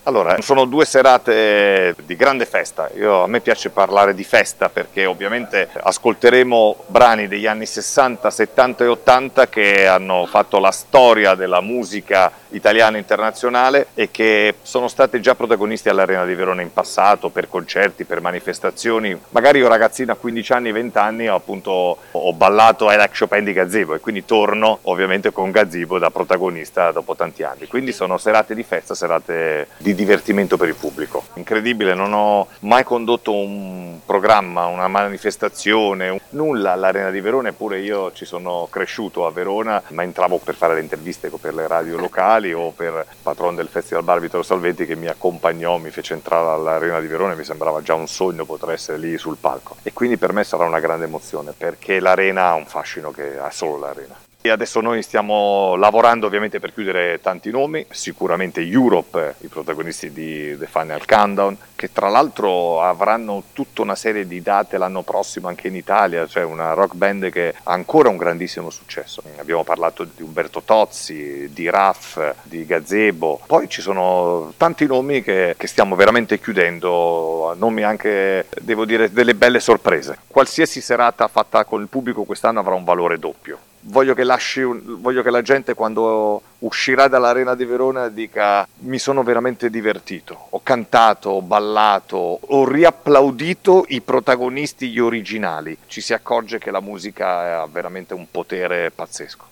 La presentazione delle serate è stata l’occasione per Amadeus di tornare a Verona e raccontare